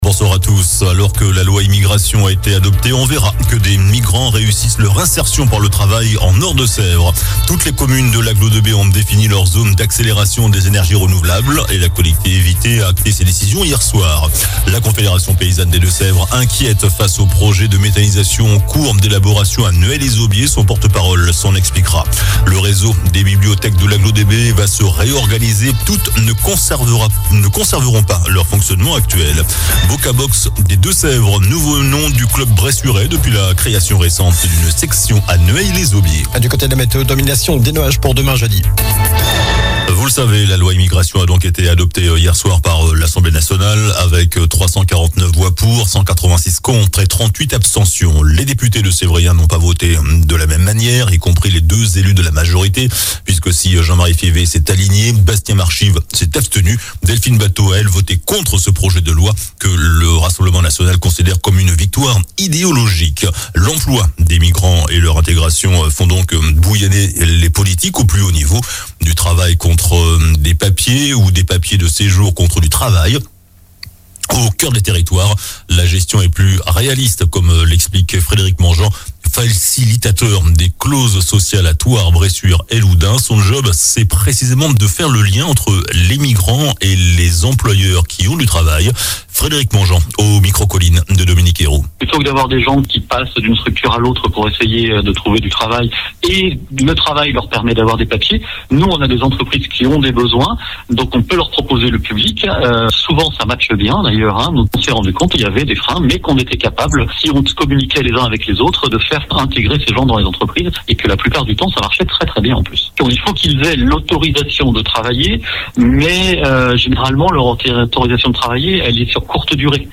JOURNAL DU MERCREDI 20 DECEMBRE ( SOIR )